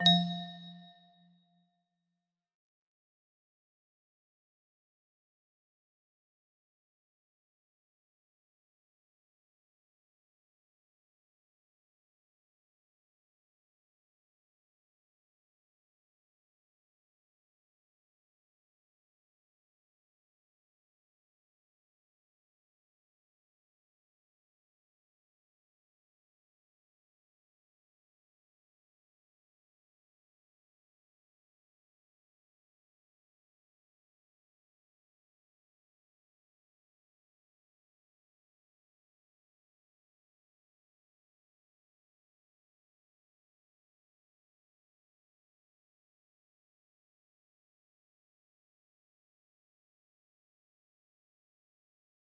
C music box melody
Grand Illusions 30 (F scale)